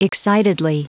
Prononciation du mot excitedly en anglais (fichier audio)
Prononciation du mot : excitedly
excitedly.wav